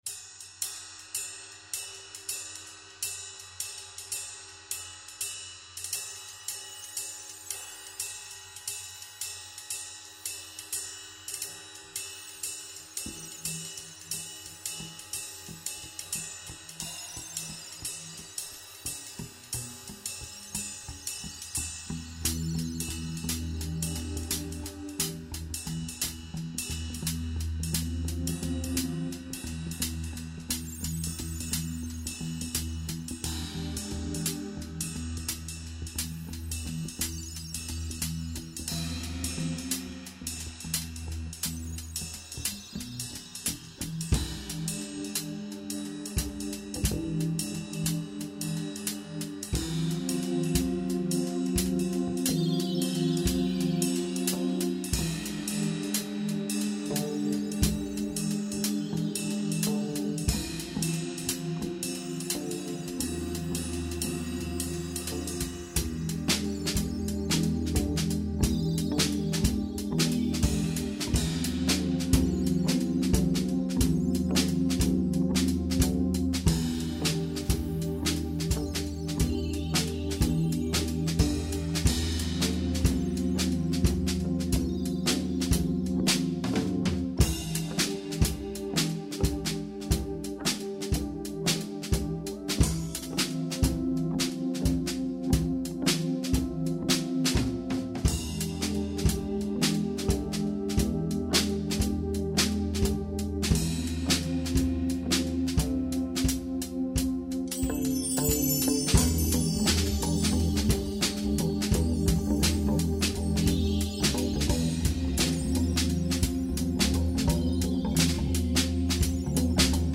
drums
organ, pianoes, keyboards & synthesizers
bass